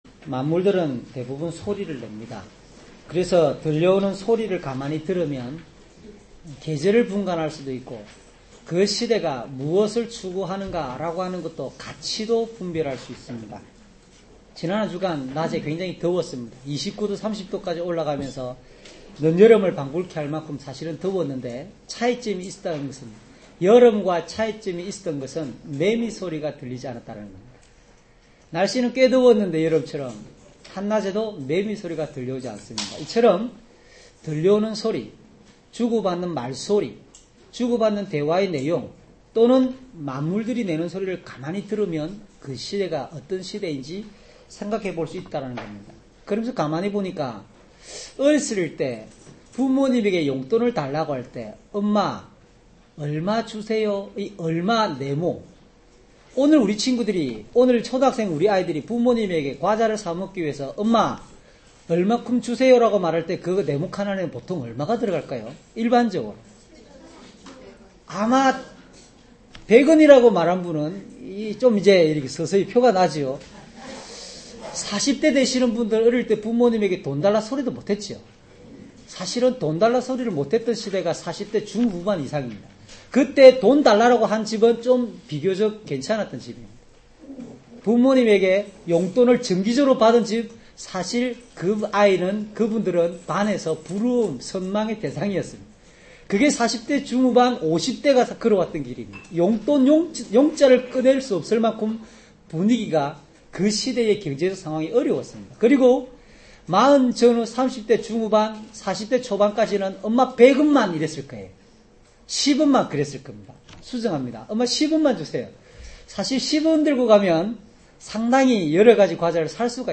주일설교 - 08년 10월 19일 " 귀 기울여 들어야 할 말씀이 있습니다."